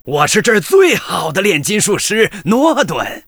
文件 文件历史 文件用途 全域文件用途 Rt_tk_01.ogg （Ogg Vorbis声音文件，长度3.3秒，107 kbps，文件大小：43 KB） 源地址:游戏语音 文件历史 点击某个日期/时间查看对应时刻的文件。 日期/时间 缩略图 大小 用户 备注 当前 2018年5月20日 (日) 14:52 3.3秒 （43 KB） 地下城与勇士  （ 留言 | 贡献 ） 分类:诺顿·马西莫格 分类:地下城与勇士 源地址:游戏语音 您不可以覆盖此文件。